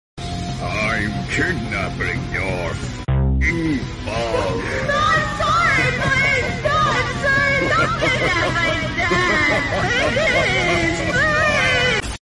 Roblox Forsaken kidnap sound effects free download